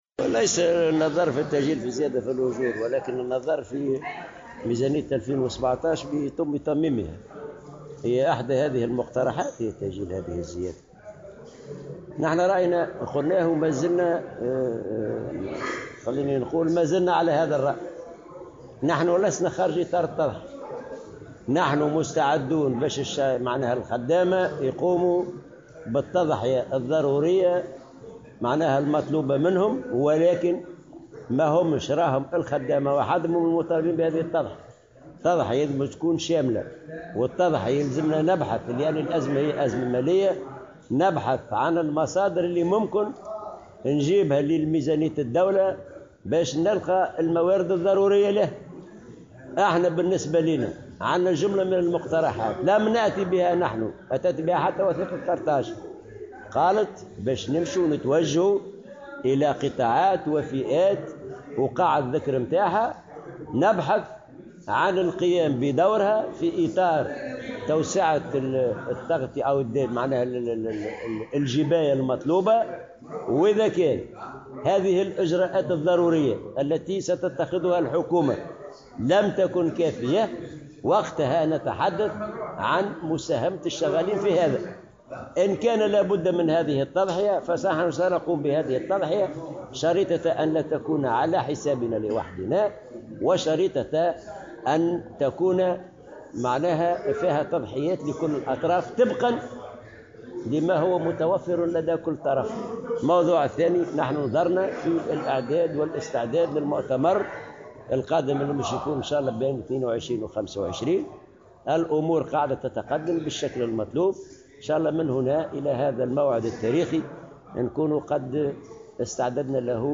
ودعا الامين العام للاتحاد العام التونسي للشغل، حسين العباسي، الذي ترأس الهيئة، في تصريح للجوهرة أف أم، دعا الحكومة إلى تنويع موارد الميزانية العمومية، وذلك من خلال مقاومة الفساد، والتهرب الجبائي والتهريب، والحرص على استخلاص الديون، وتوظيف الاداء على المواد غير الضرورية، والضغط على الأسعار، حتى لا يتحمل الشغالون بمفردهم التضحيات.